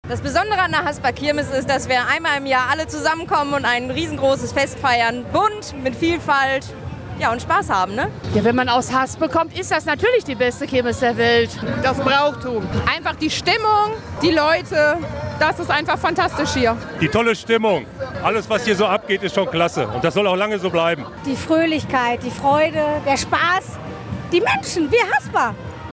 Hasper Kirmeszug und Kirmes
tonreihe-hasper-kirmes.mp3